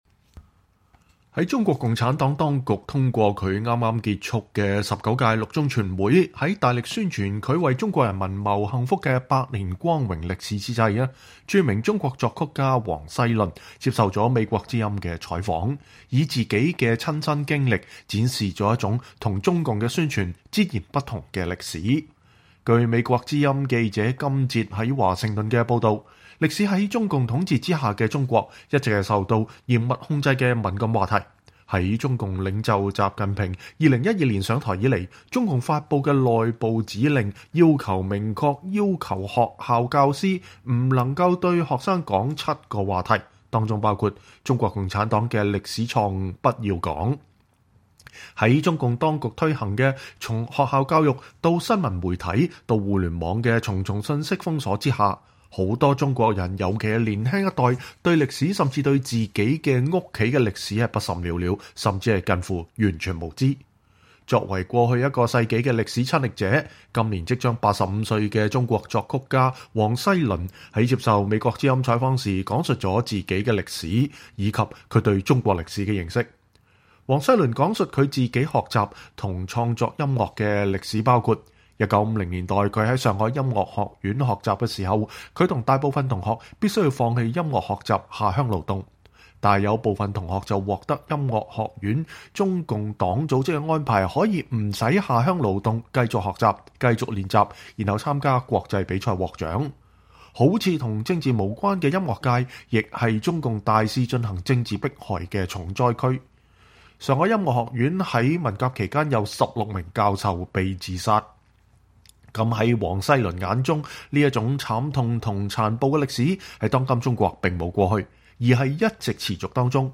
專訪作曲家王西麟(2)： 從音樂看個人與中國的歷史